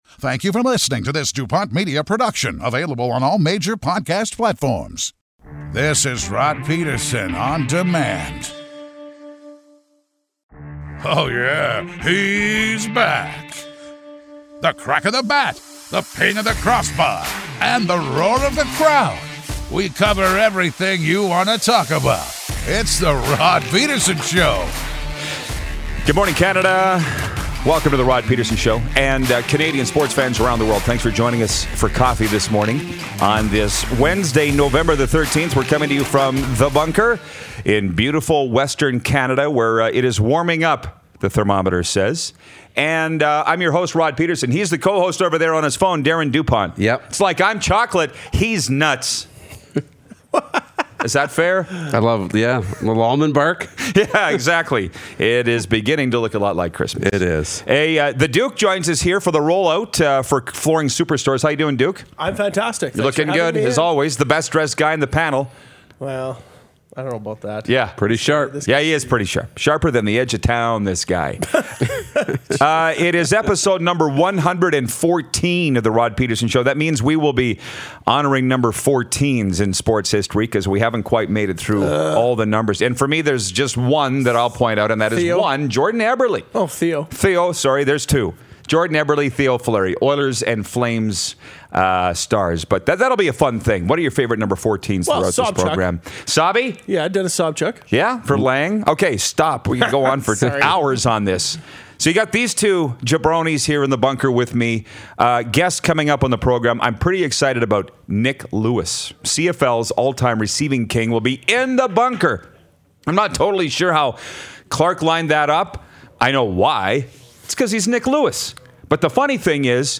NHL Scout and Broadcaster, Rich Sutter calls in!